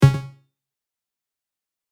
Blip 2.mp3